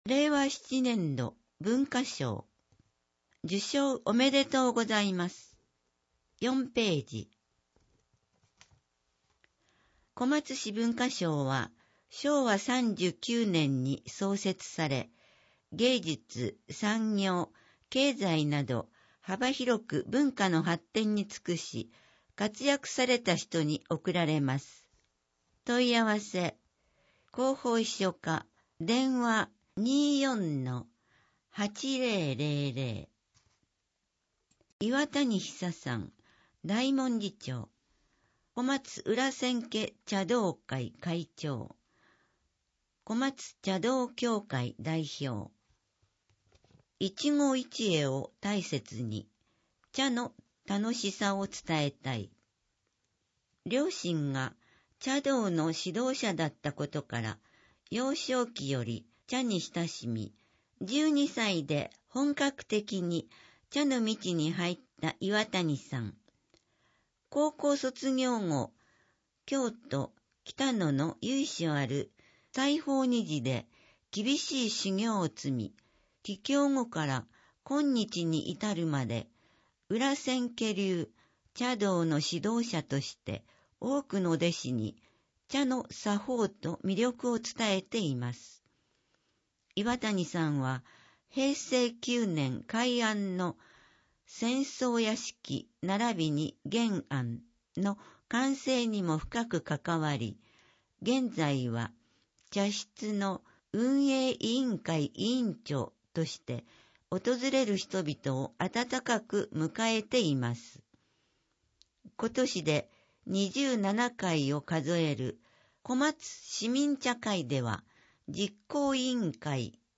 広報こまつの音訳は音訳ボランティアグループ「陽だまり会」の皆さんの協力で行っています。